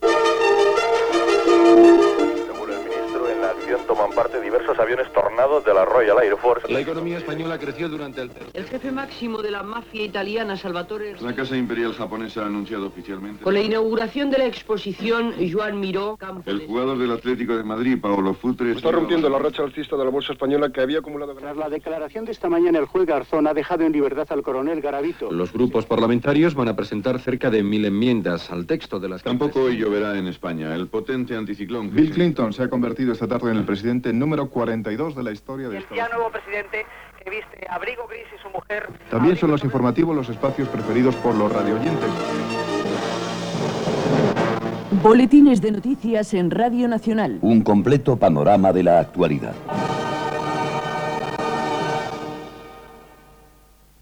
Promoció dels "Boletines de noticias" de Radio Nacional